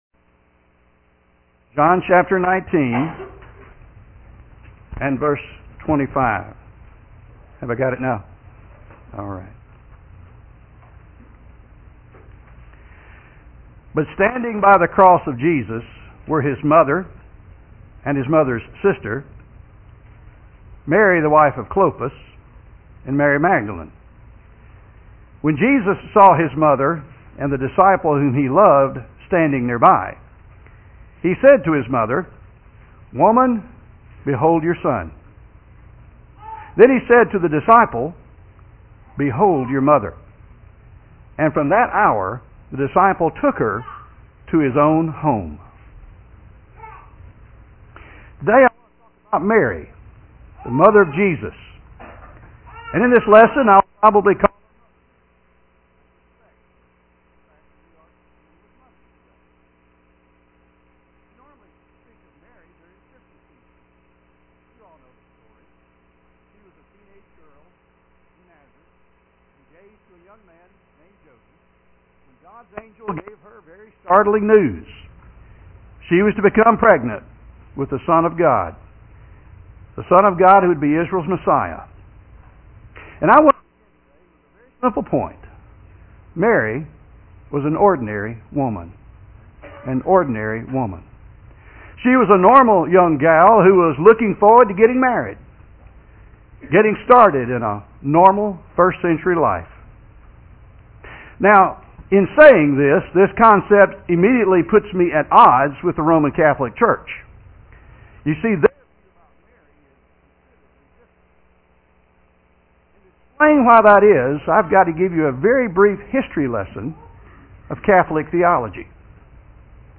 (NOTE: Due to technical difficulties, portions of this sermon did not record. Recording stops at 11:10 but resumes at 12:45.)